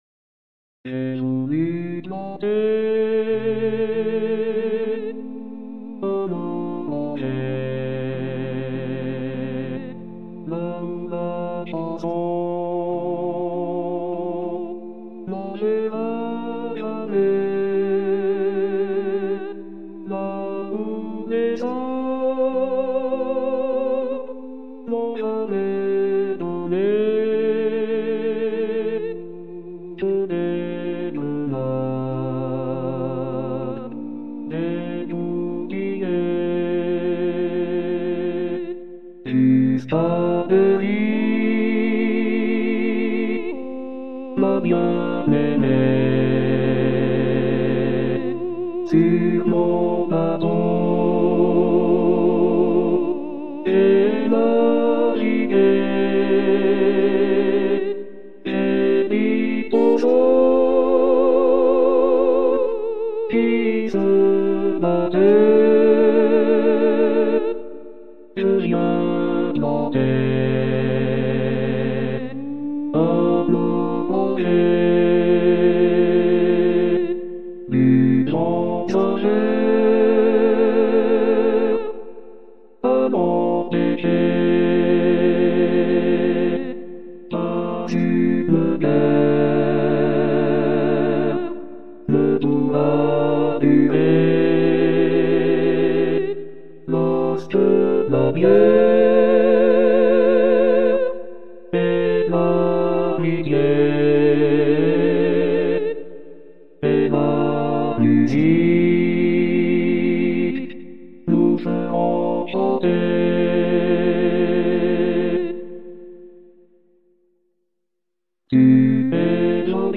Les abréviations sont inchangées: A = Altis, B= Basses, C = Choeur, F= Femmes,
51_ballade_nord_irlandaise_b_voix.mp3